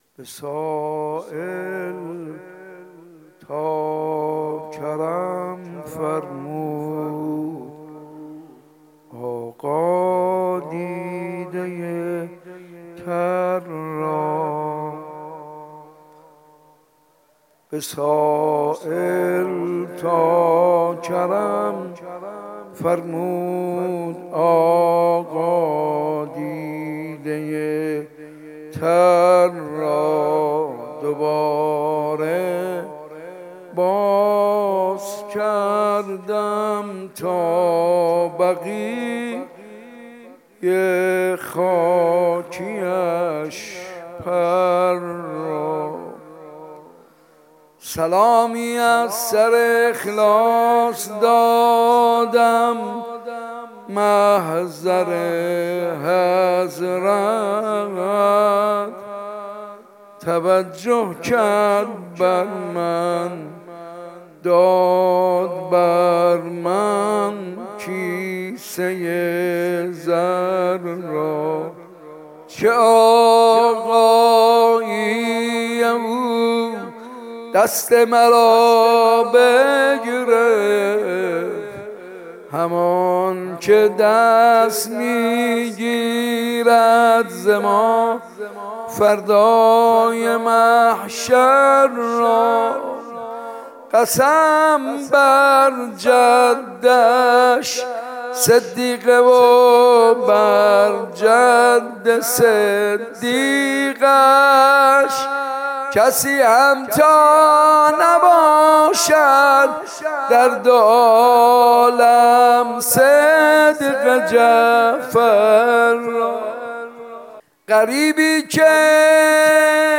روضه
ویژه شهادت امام جعفر صادق (علیه‌السلام)